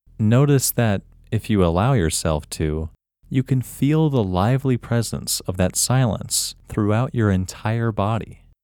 WHOLENESS English Male 6
WHOLENESS-English-Male-6.mp3